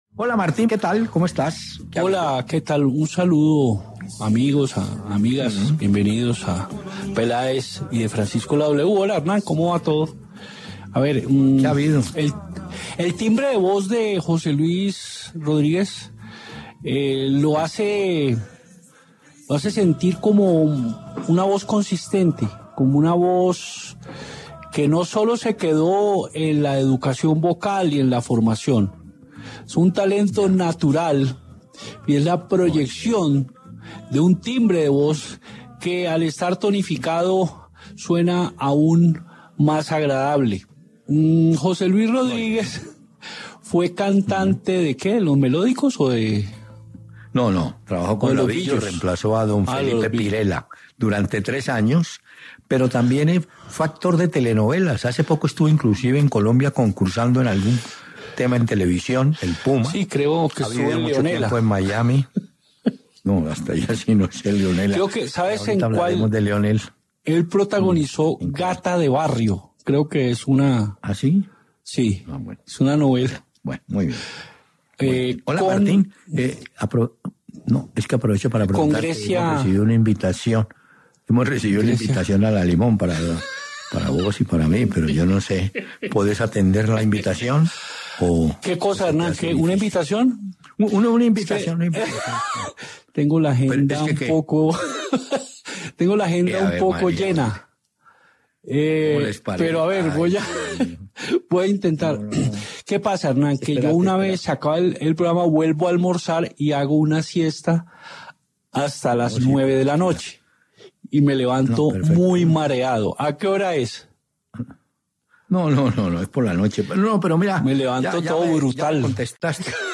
Una hora para charlar de lo que más nos gusta: el fútbol, el de ayer y el de hoy, con grandes anécdotas. Todo, acompañado de buena música.